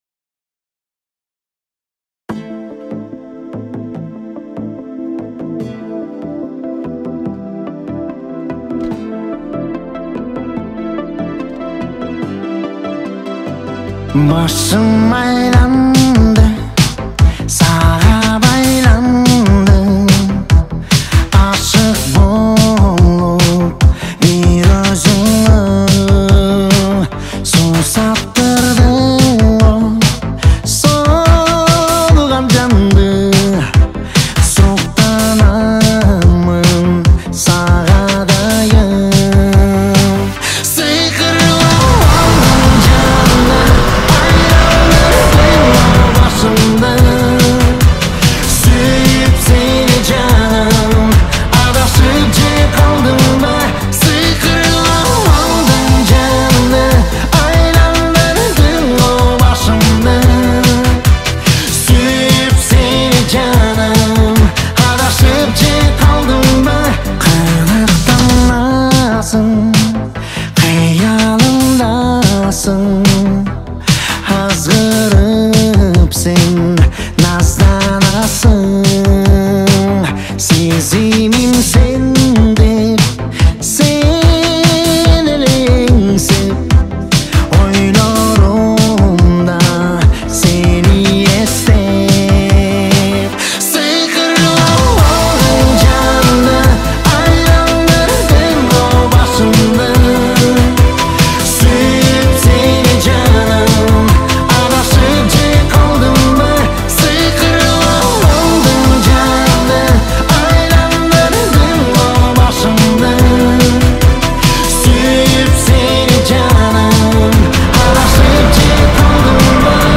• Киргизские песни